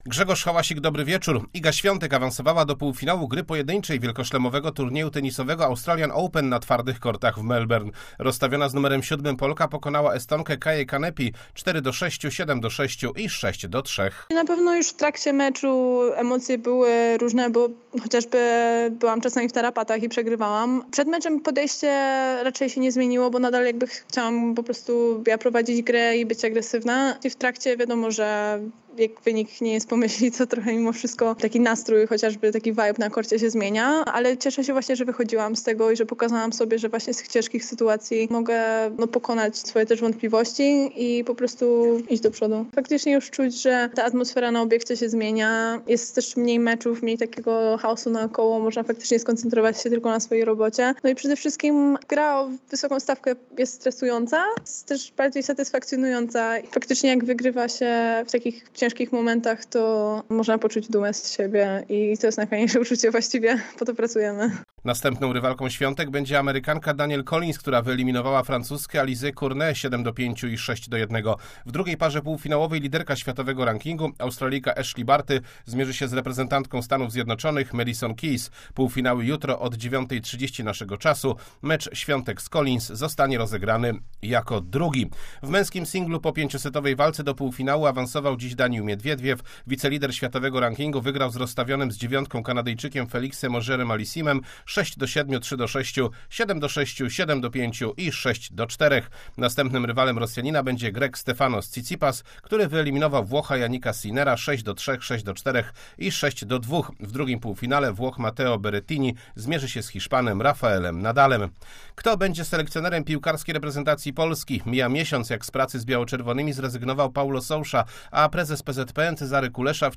26.01.2022 SERWIS SPORTOWY GODZ. 19:05